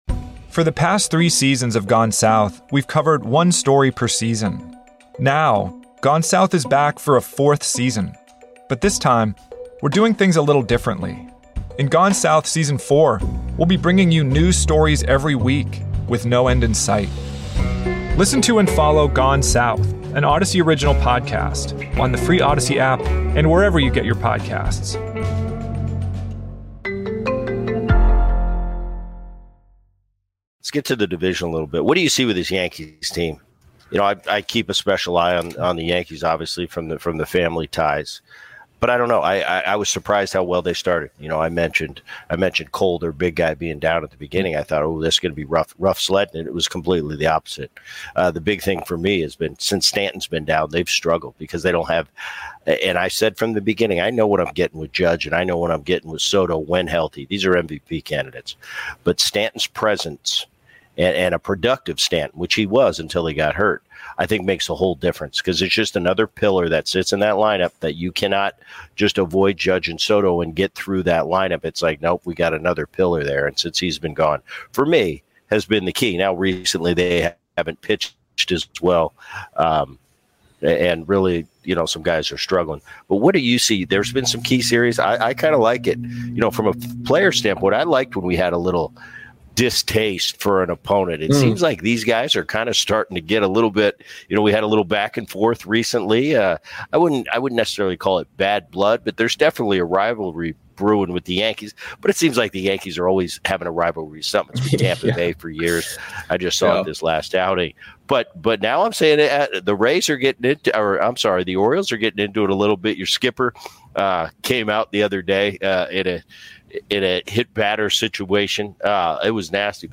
From 'The Boone Podcast' (subscribe here): Bret Boone and Orioles broadcaster Kevin Brown discuss the recent play from the Yankees and why we may see them be one of the more active teams at the trade deadline.